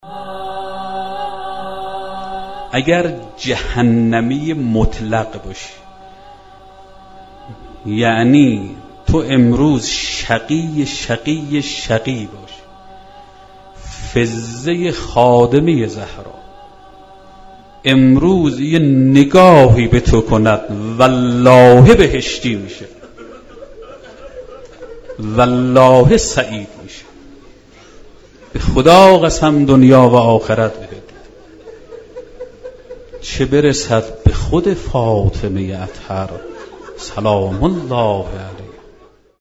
ایکنا/ سخنرانی شنیدنی درباره نگاه حضرت فاطمه زهرا(س) را بشنوید.